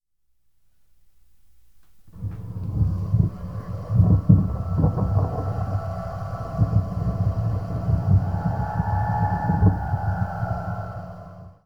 Data Acquisition and Analysis: Binaural Recordings
The Short-time Fourier Transform (spectrogram) above shows the time evolution of the spectral content of the Aeolian harp up to a maximum frequency of 2,000 Hz.